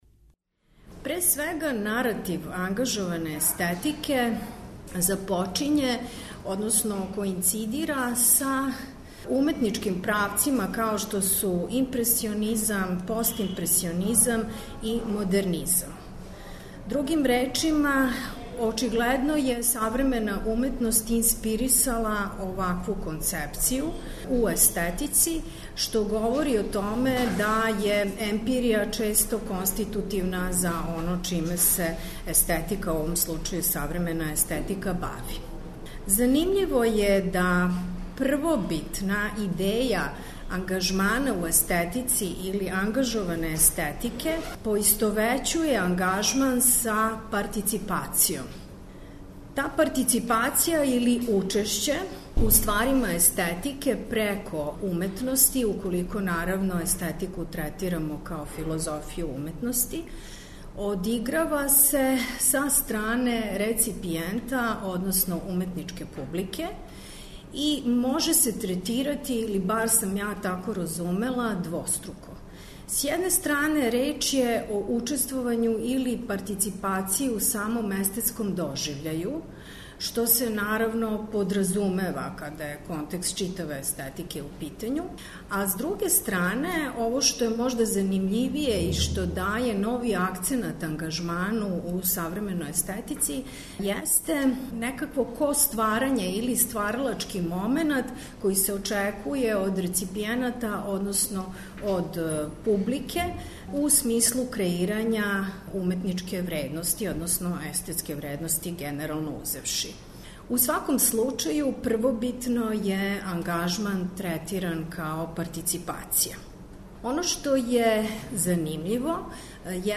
Снимак је забележен 20. маја у Заводу за проучавање културног развитка у Београду.